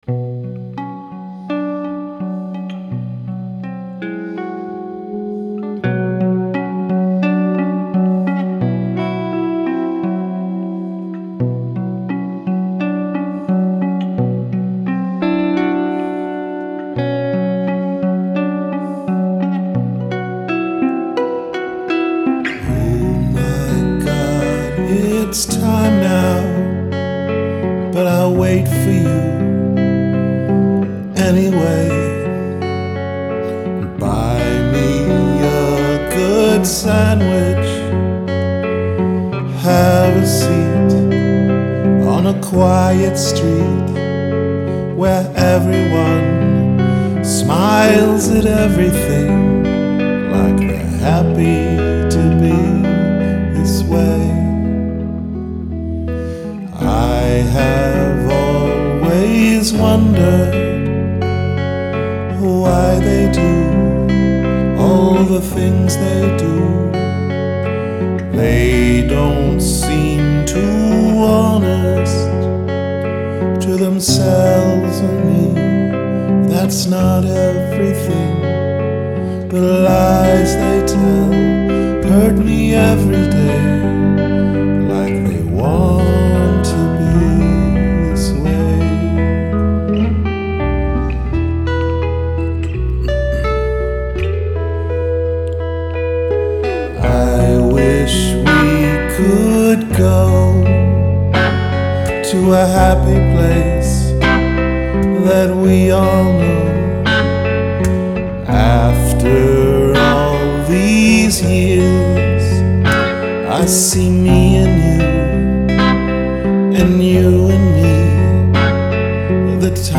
Rehearsals 17.3.2012